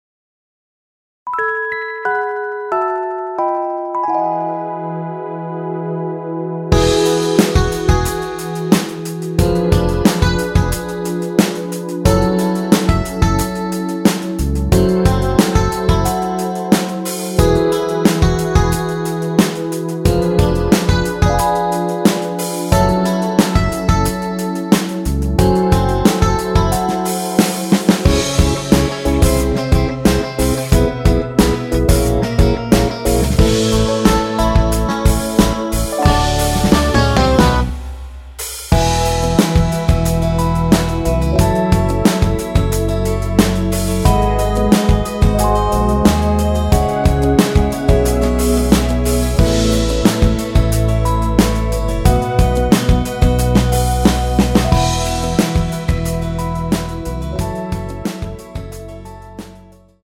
원키에서(+5)올린 MR입니다.
앞부분30초, 뒷부분30초씩 편집해서 올려 드리고 있습니다.
중간에 음이 끈어지고 다시 나오는 이유는